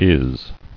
[is]